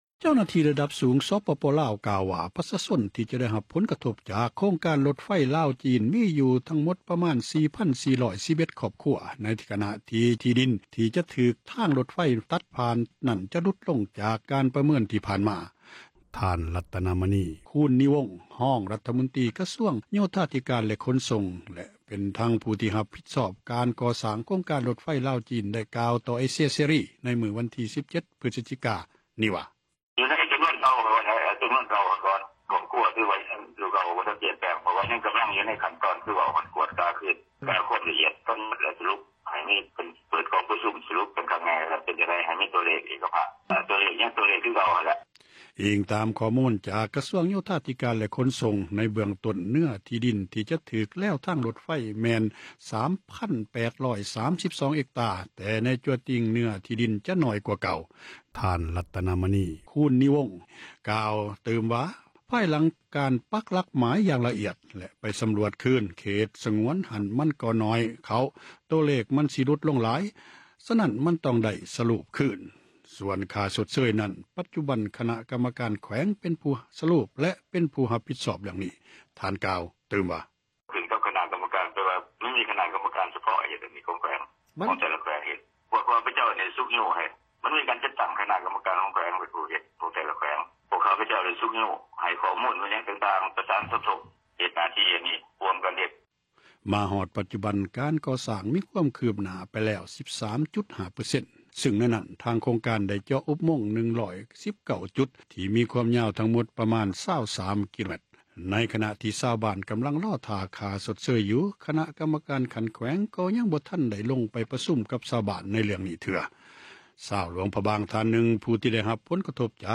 ທ່ານ ລັດຕະນະມະນີ ຄູນນີວົງ, ຮອງຣັຖມົນຕຼີ ກະຊວງໂຍທາທິການ ແລະ ຂົນສົ່ງ ແລະ ທັງເປັນຜູ້ຮັບຜິດຊອບ ການກໍ່ສ້າງ ໂຄງການທາງຣົດໄຟ ລາວ-ຈີນ, ໄດ້ກ່າວຕໍ່ເອເຊັຽເສຣີ ໃນວັນທີ 17 ພຶສຈິກາ ວ່າ: